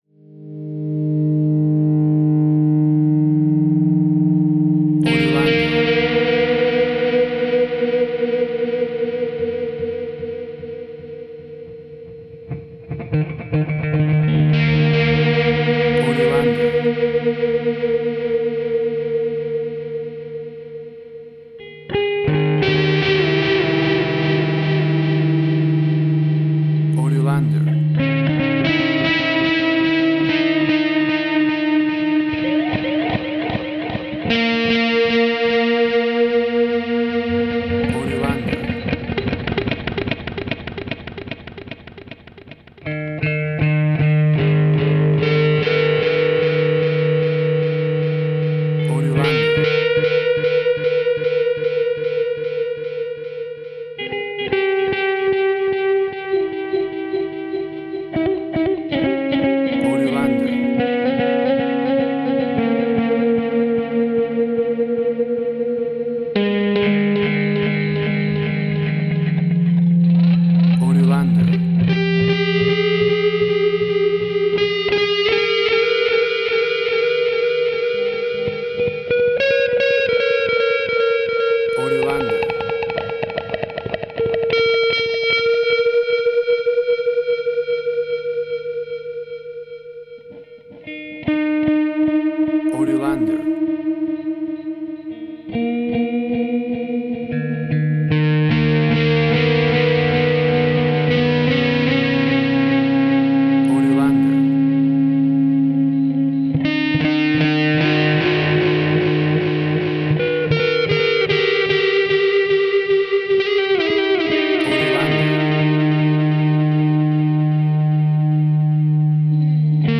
WAV Sample Rate: 24-Bit stereo, 44.1 kHz